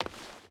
Stone Run 5.ogg